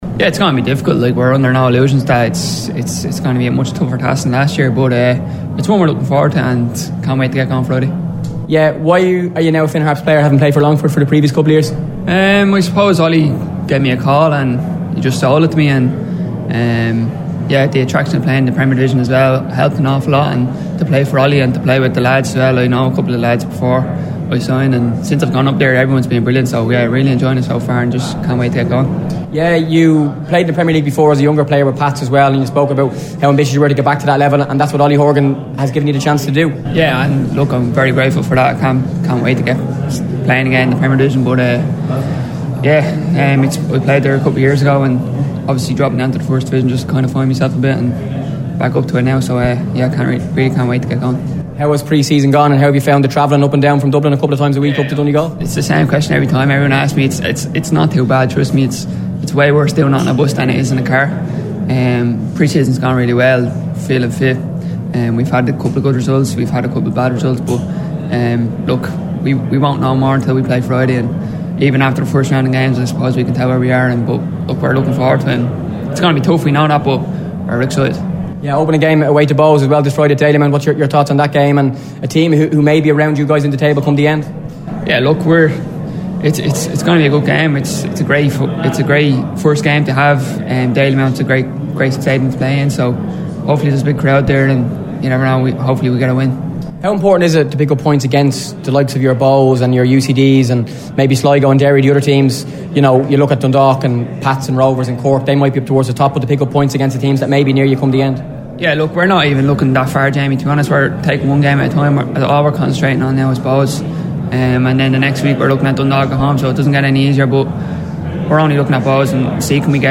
Speaking at the League launch